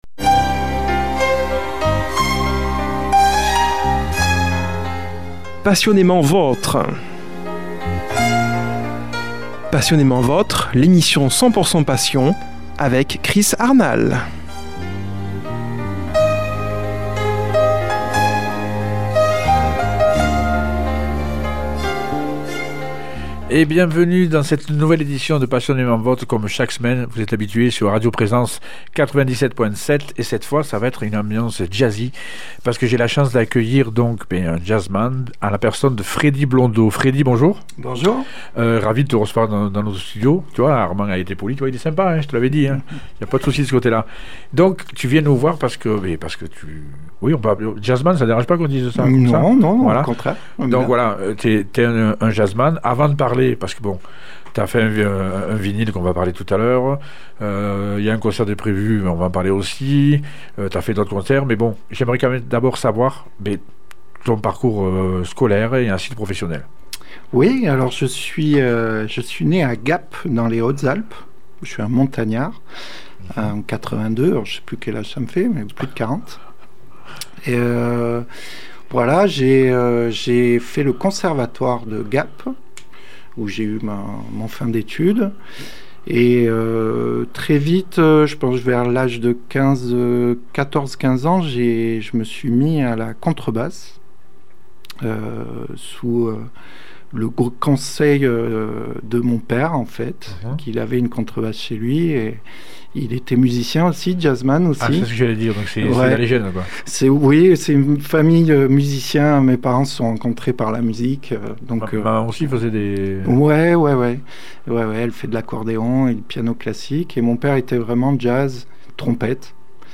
Ambiance Jazzy au studio de Radio Présence Figeac